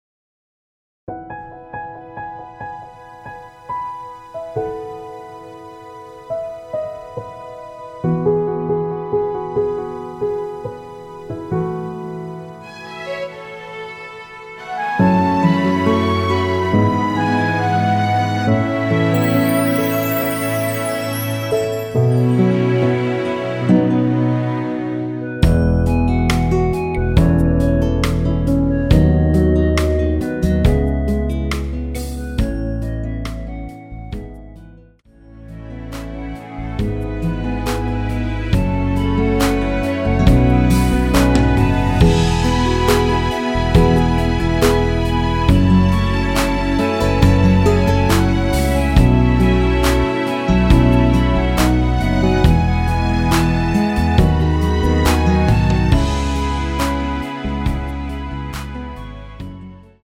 원키 멜로디 포함된 MR입니다.
앞부분30초, 뒷부분30초씩 편집해서 올려 드리고 있습니다.
중간에 음이 끈어지고 다시 나오는 이유는